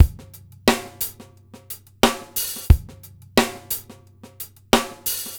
SMP DRMDRY-L.wav